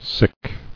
[sick]